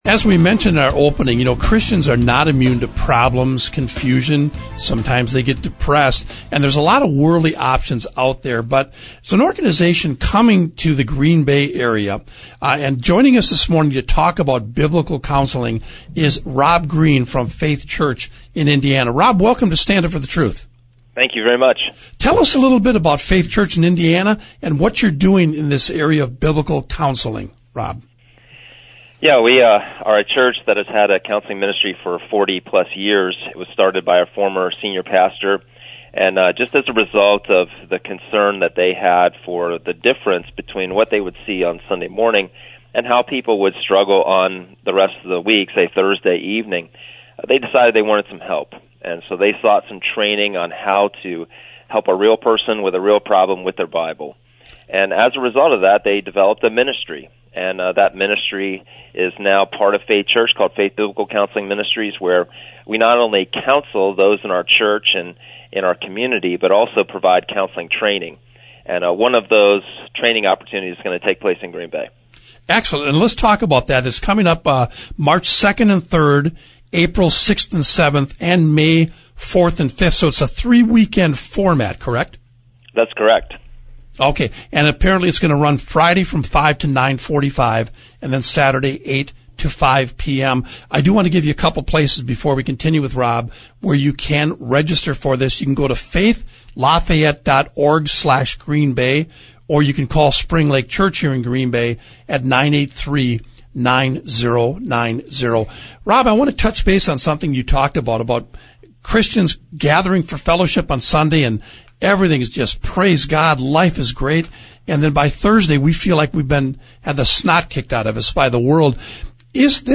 The following interview